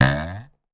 Boink_v3
boing boink jump sound effect free sound royalty free Animals